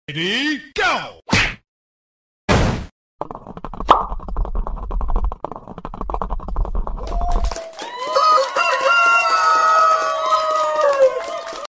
Moto Satisfying video with chicken sound effects free download